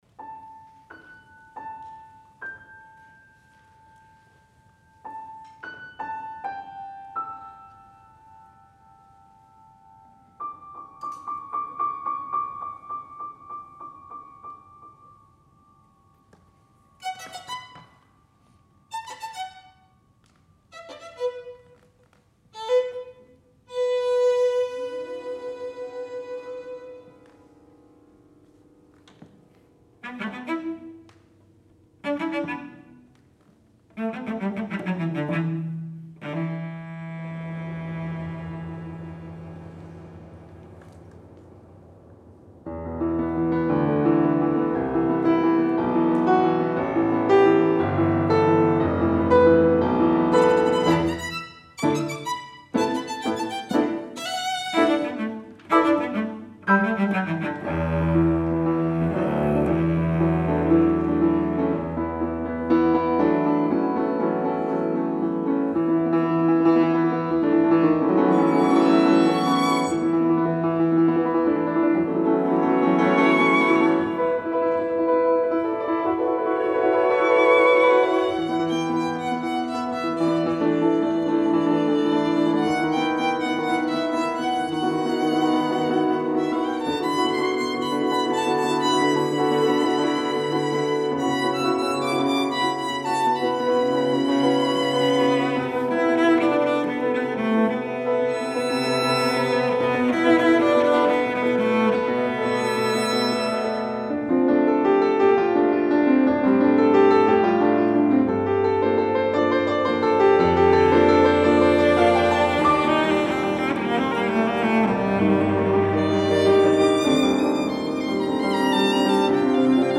for Piano Trio (2015)